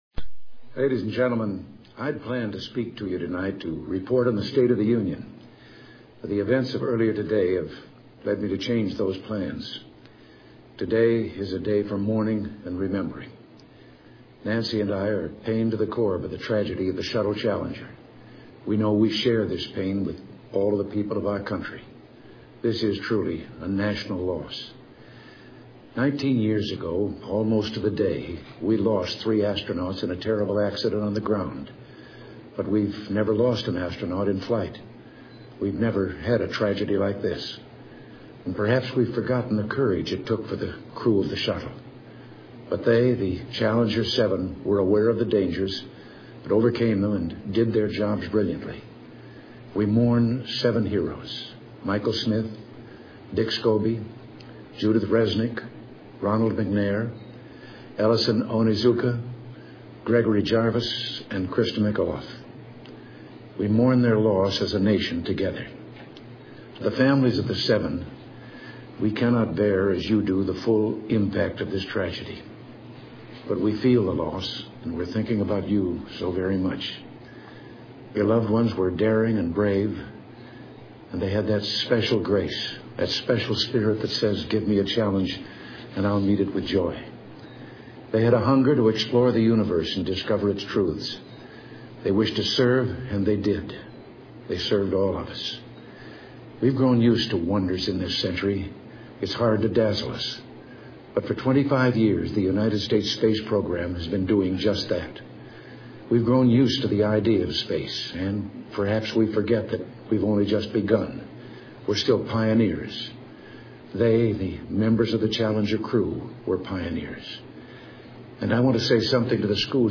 美国经典英文演讲100篇:Shuttle''Challenger''Disaster Address 听力文件下载—在线英语听力室